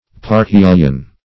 Parhelion \Par*hel"ion\, n.; pl. Parhelia.